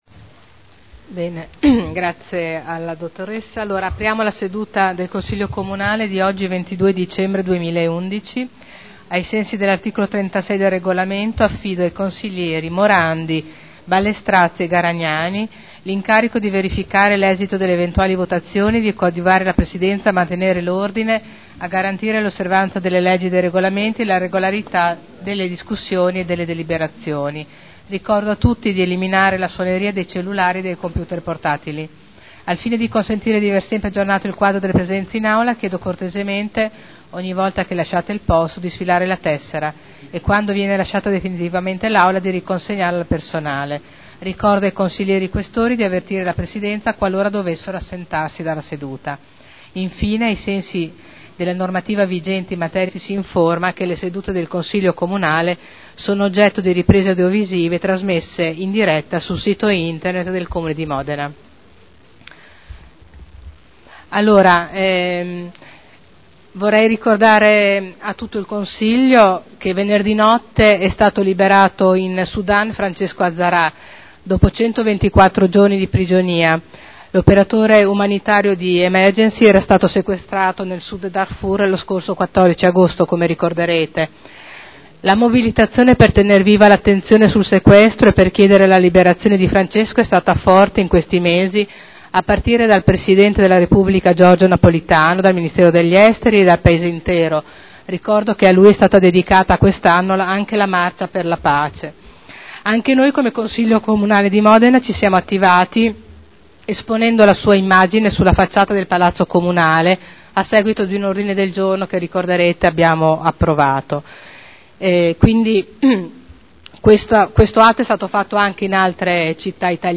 Seduta del 22/12/2011. Apertura lavori